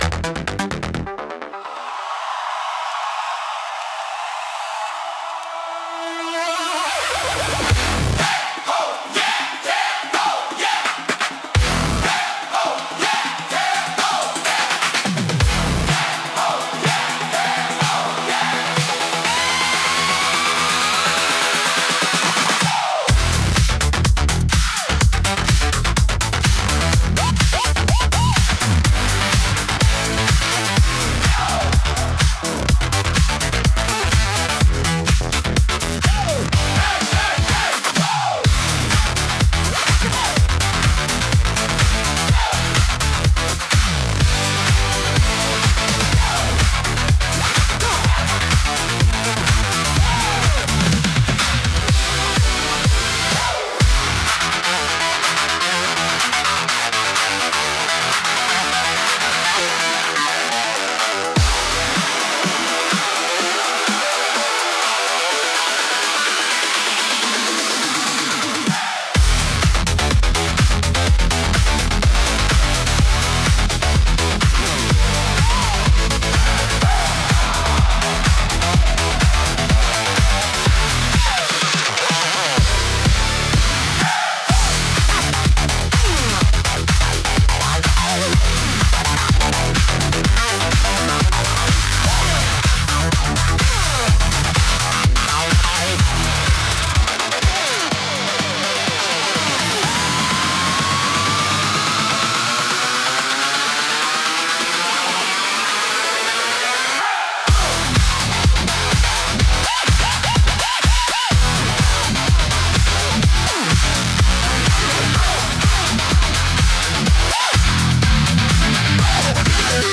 Instrumental - Bass Gripped & Compressed